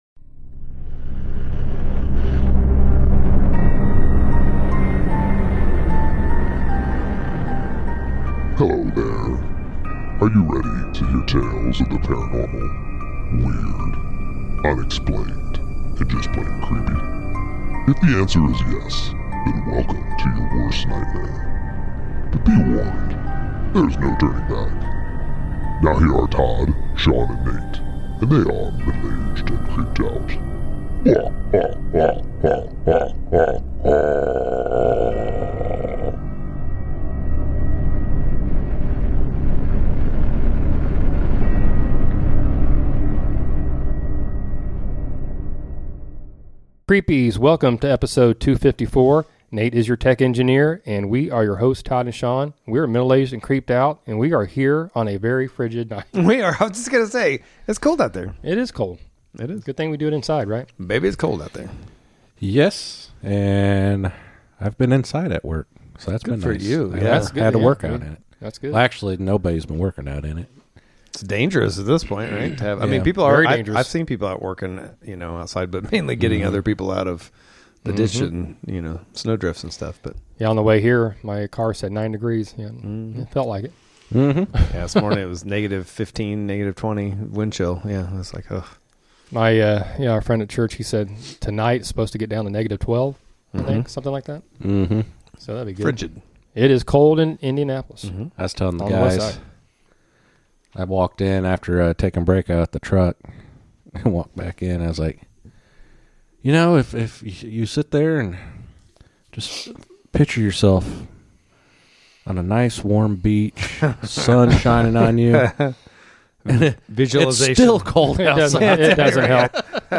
The guys’ discussion is “out of this world” good…The Kecksburg UFO Incident occurred on December 9, 1965, when a bright fireball was seen streaking across the skies of six U.S. states and Canada, including Pennsylvania, Michigan, and Ontario!!!